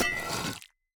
Minecraft Version Minecraft Version snapshot Latest Release | Latest Snapshot snapshot / assets / minecraft / sounds / item / axe / wax_off1.ogg Compare With Compare With Latest Release | Latest Snapshot
wax_off1.ogg